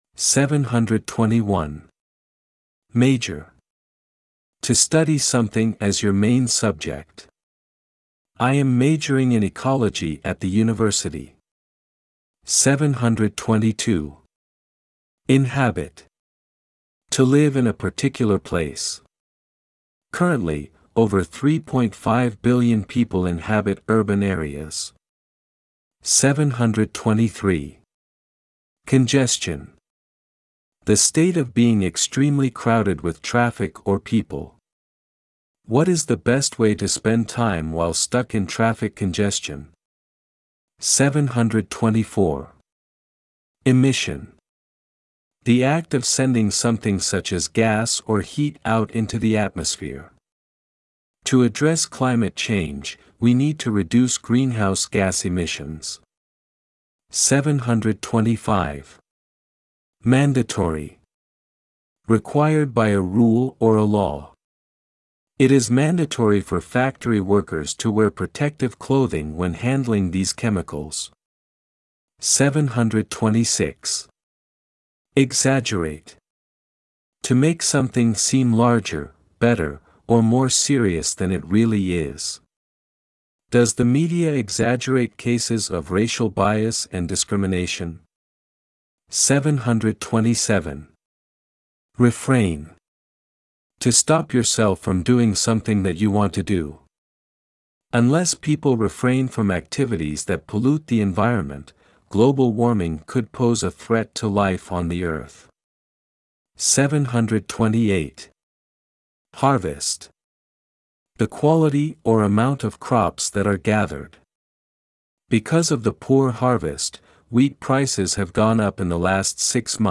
ネイティブ音声が共通テスト英語キーワードを単語・英英定義・例文の順で読み上げるyoutube【共通テスト英語キーワード820】共通テスト英語キーワード820単語・英英定義・例文音声を作成しました。